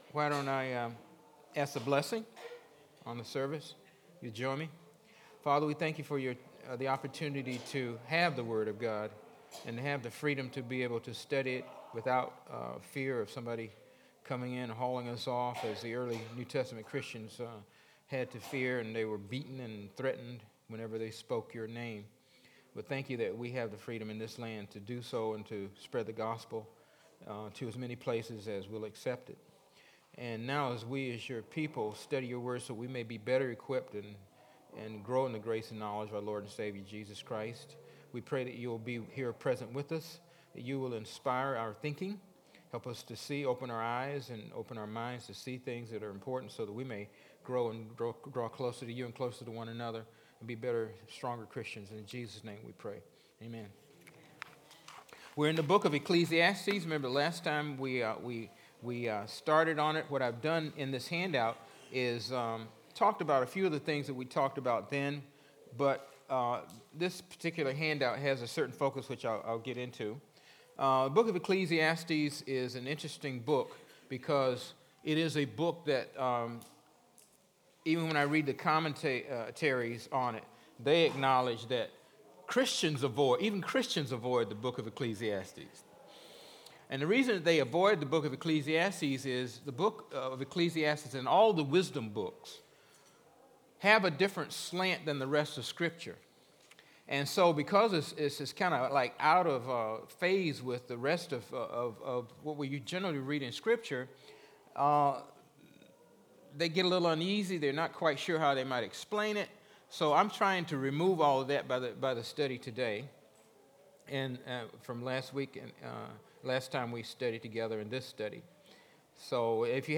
Bible Study: 4/12/15